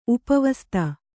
♪ upa vasta